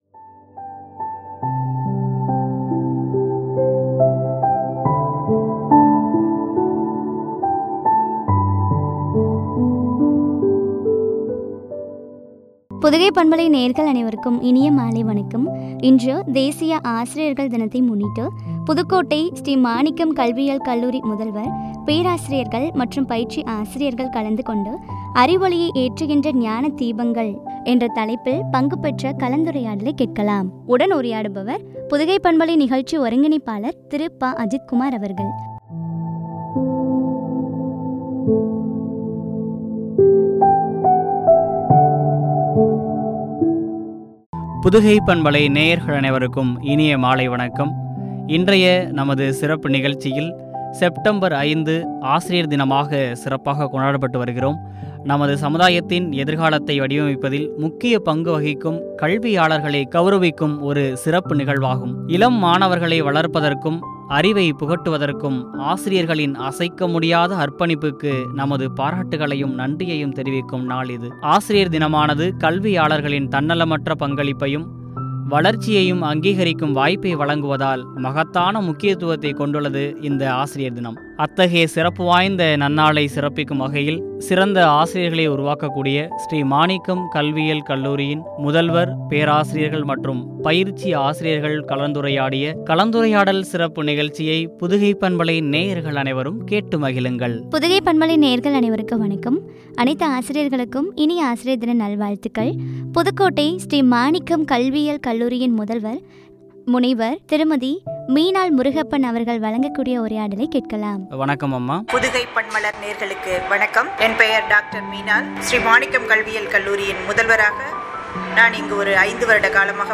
பேராசிரியர்கள் மற்றும் பயிற்சி ஆசிரியர்கள்
உரையாடல்